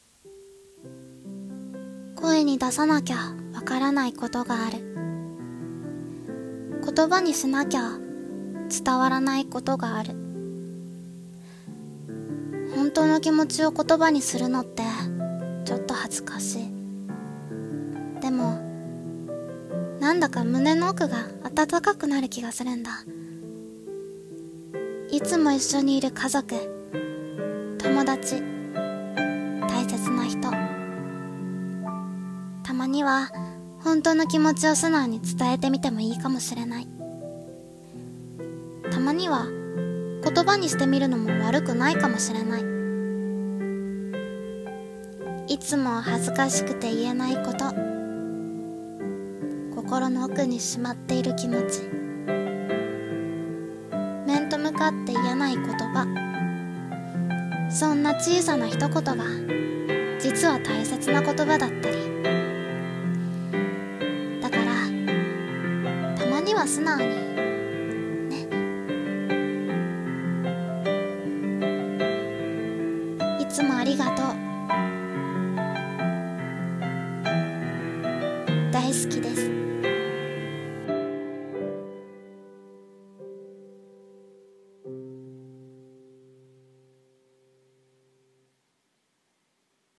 さんの投稿した曲一覧 を表示 [声劇･朗読]たまには素直に[台本